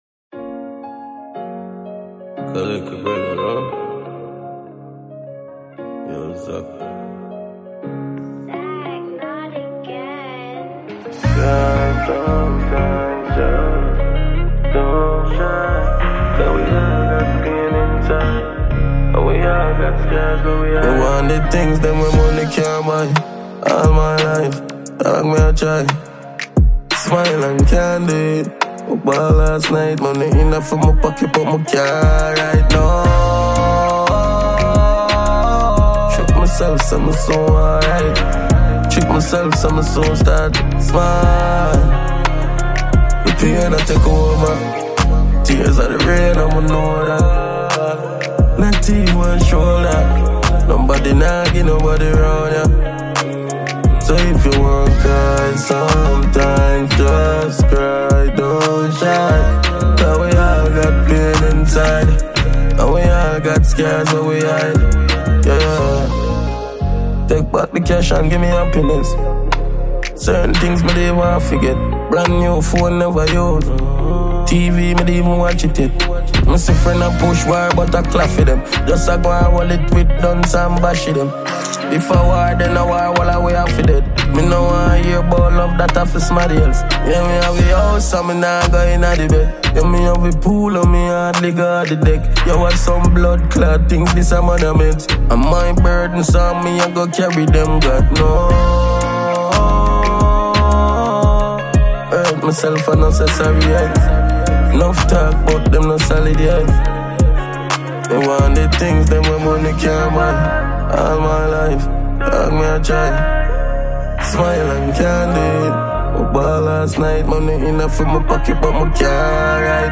Award-winning Jamaican dancehall musician